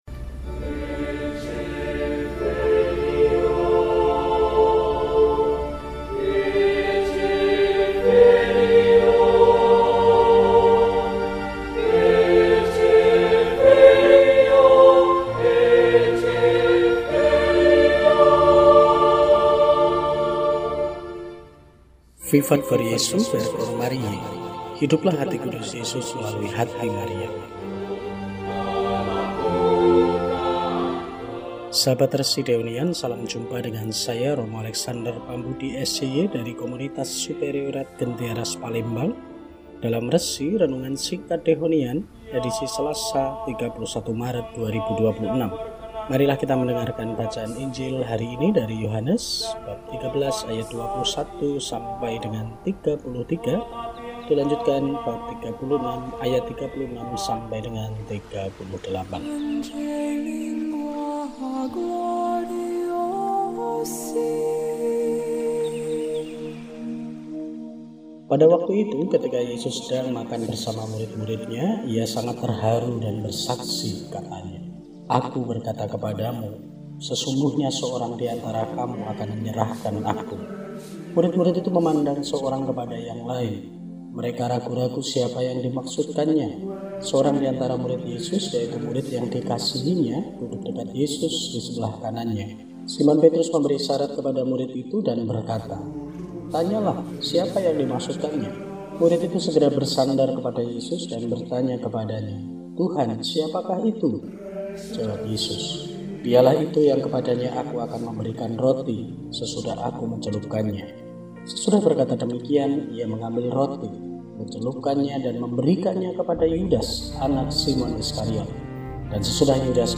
Selasa, 31 Maret 2026 – Hari Selasa dalam Pekan Suci – RESI (Renungan Singkat) DEHONIAN